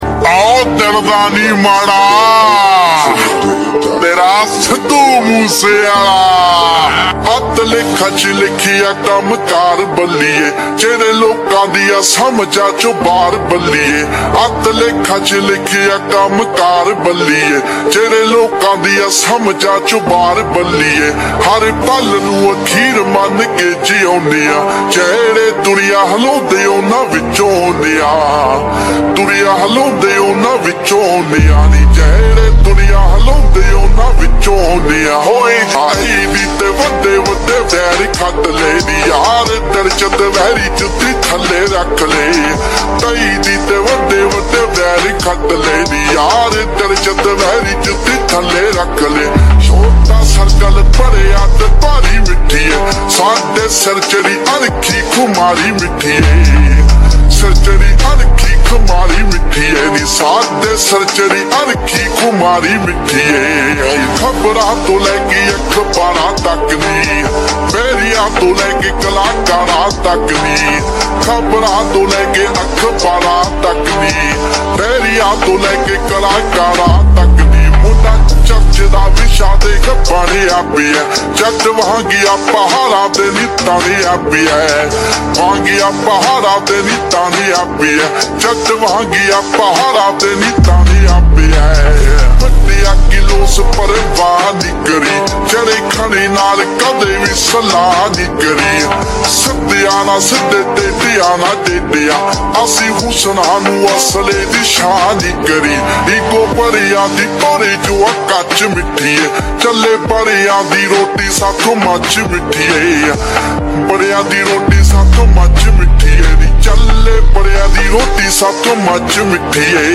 SLOW REVERB FULL SONG